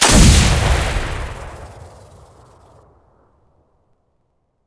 sniper1.wav